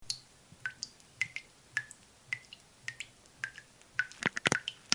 倒水2
描述：在一个小玻璃的倾吐的水